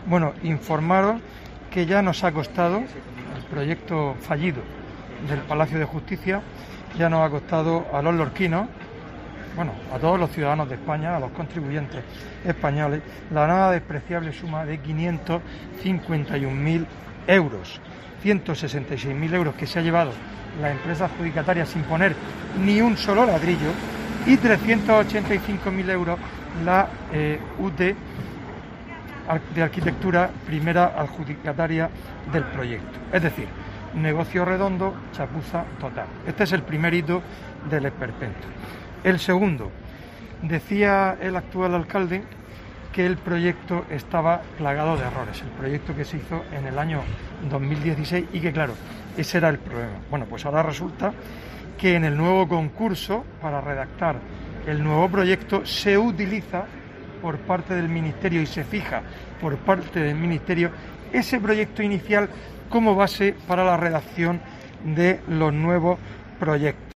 Fulgencio Gil, portavoz del PP en Lorca sobre Palacio de Justicia